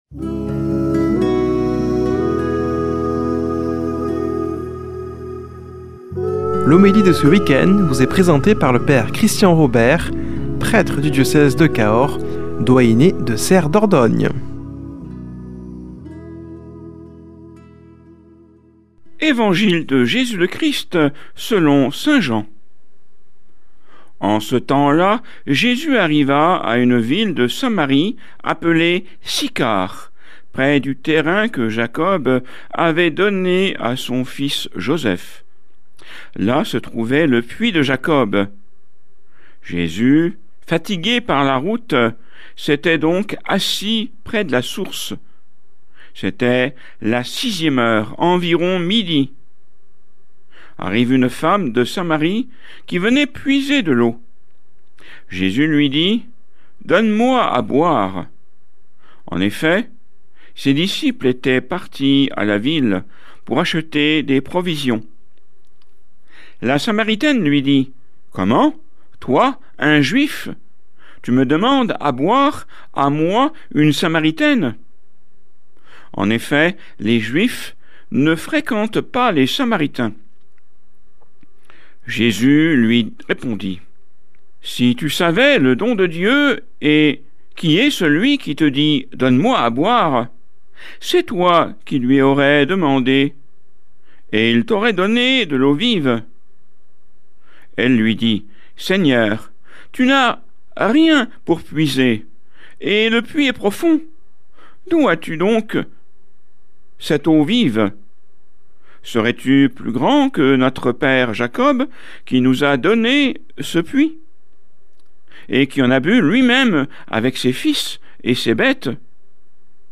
Homélie du 07 mars